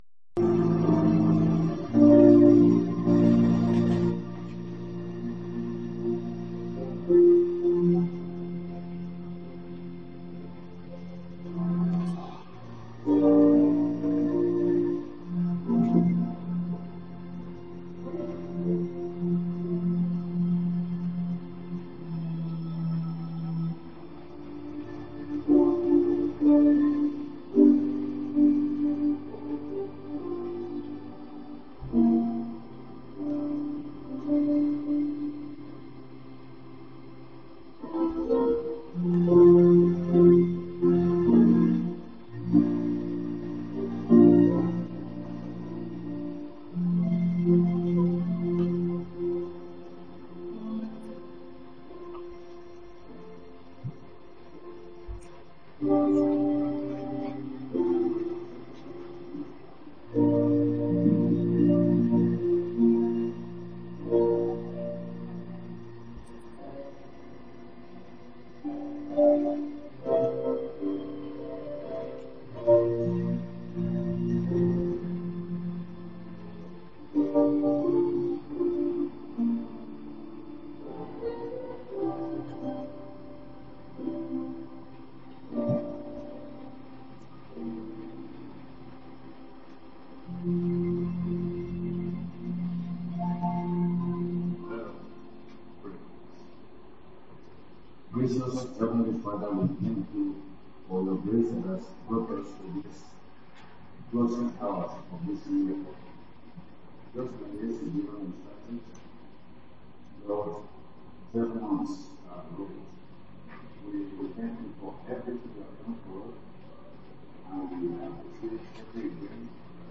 Cross-Over Service 31/12/24
Sermon Details: